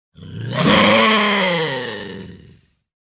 Звуки рыси
Агрессивное рычание